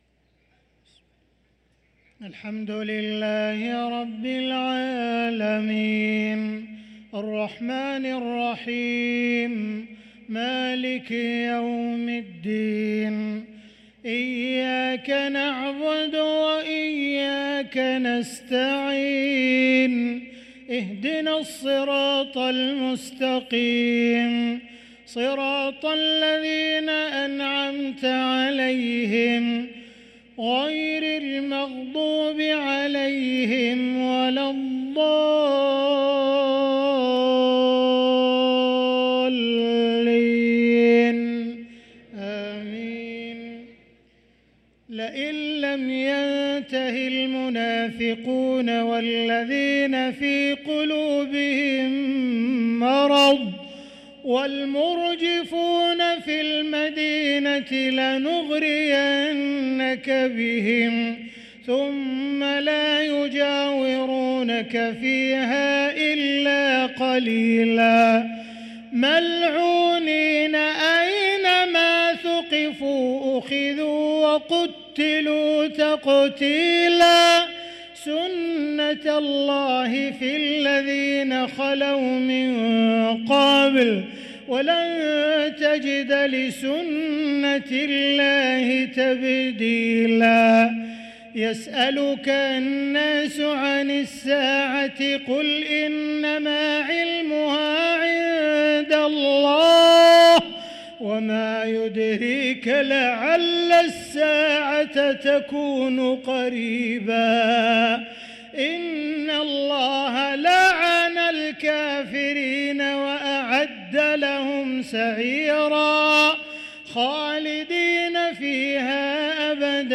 صلاة العشاء للقارئ عبدالرحمن السديس 21 جمادي الآخر 1445 هـ
تِلَاوَات الْحَرَمَيْن .